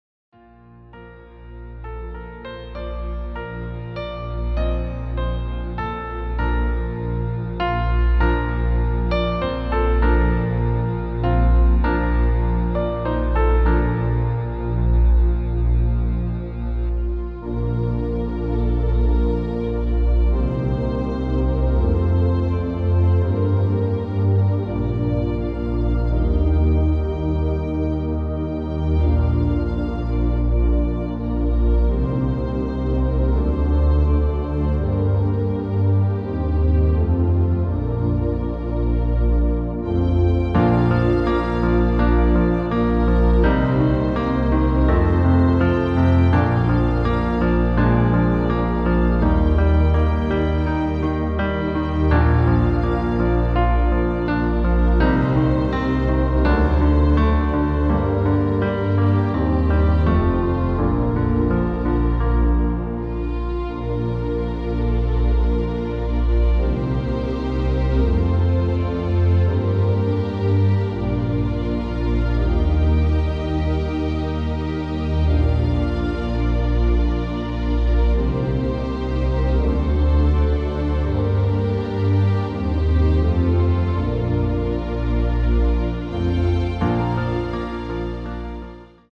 • Tonart: Ab Dur, Bb Dur (Originaltonart )
• Art: Klavierversion mit Streichern
• Das Instrumental beinhaltet NICHT die Leadstimme
• Vorsicht: 3/4 Takt Vorspiel
• Break und Wechsel auf 4/4 Takt
• Break & Tonerhöhung